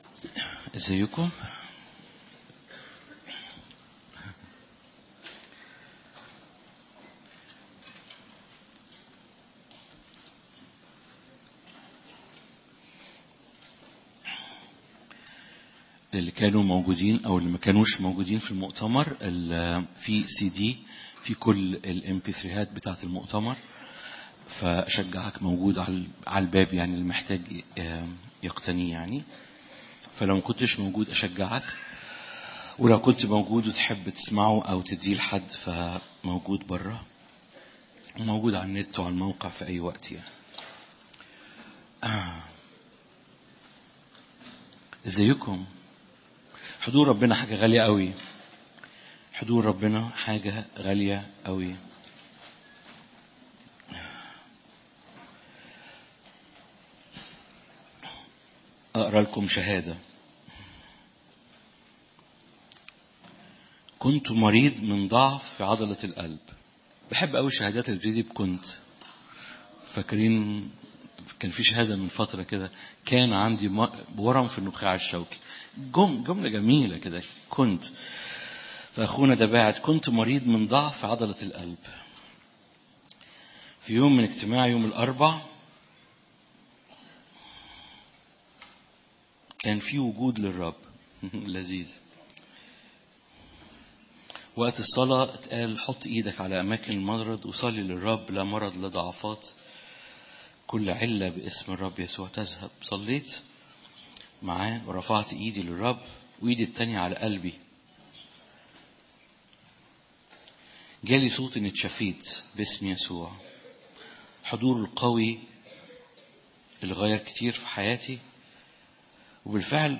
Wed+18+Feb+Sermon.mp3